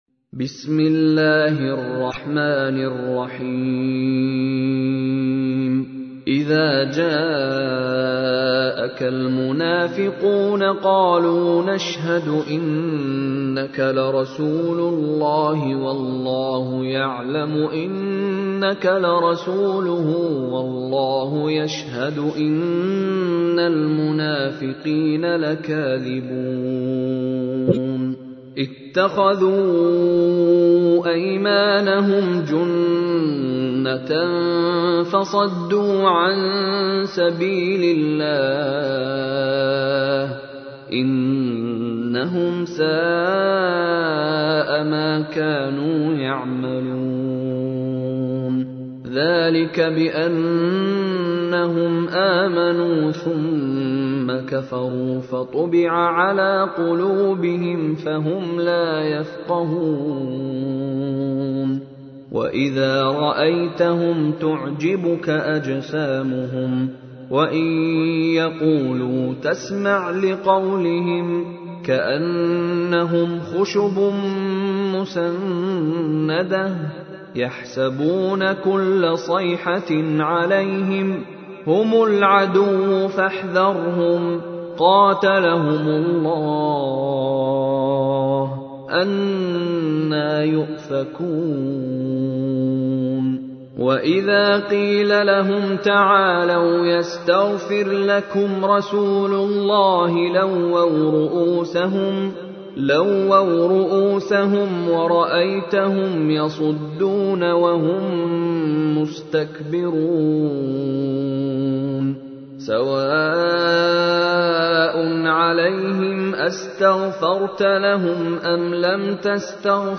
تحميل : 63. سورة المنافقون / القارئ مشاري راشد العفاسي / القرآن الكريم / موقع يا حسين